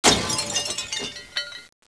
To listen to me drop my teacup -
teacup.wav